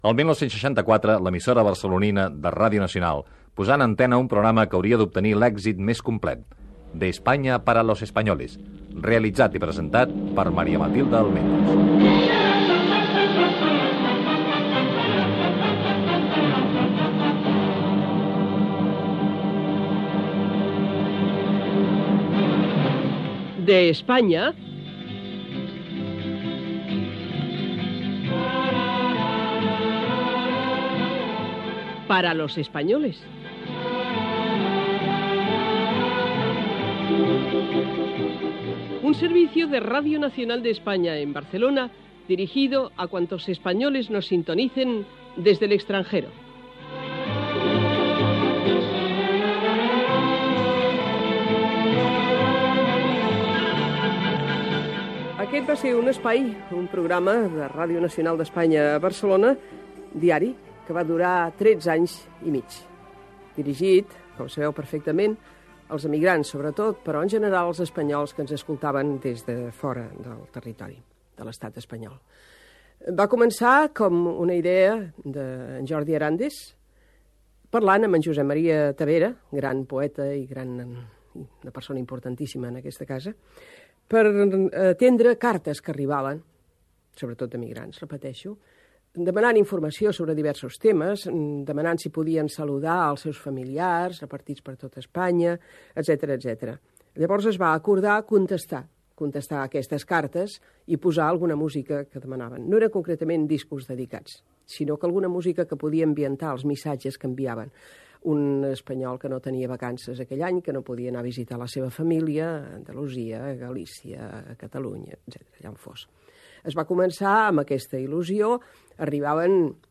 Maria Matilde Almendros parla del programa"De España para los españoles"de Radio Nacional de España que ella va presentar
Divulgació